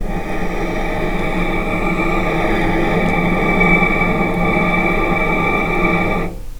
Strings / cello / sul-ponticello
vc_sp-D6-pp.AIF